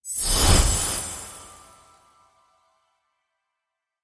my-stream-notification.mp3